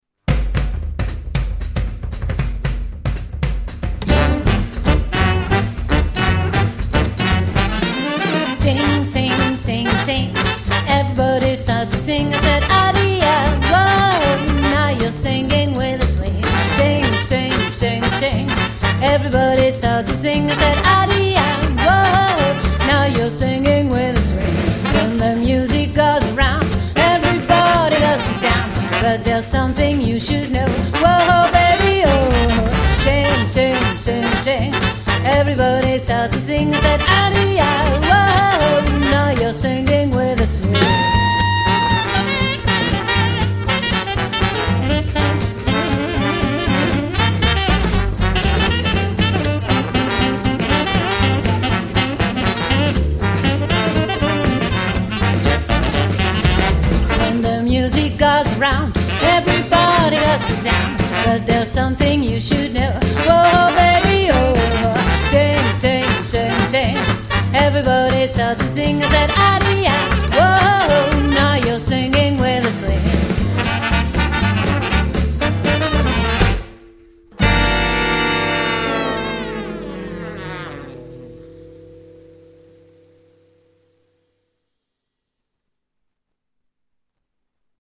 Jazz Swing Pop